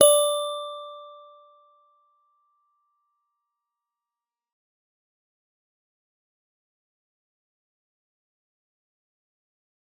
G_Musicbox-D5-f.wav